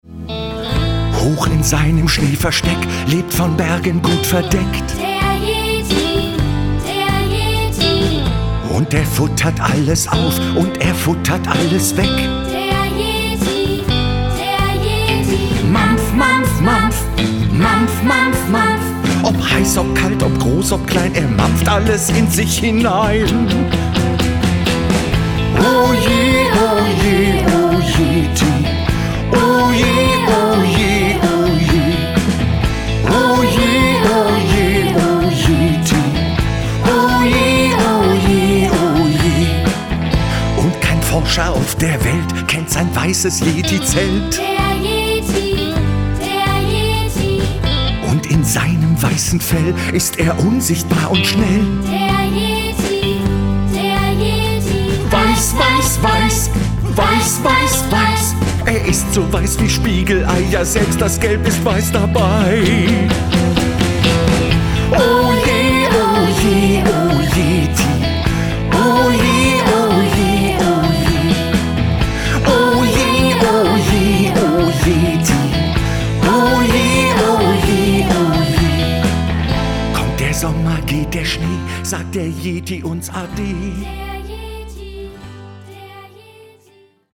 Ein winterliches Musical- und Mitmachbuch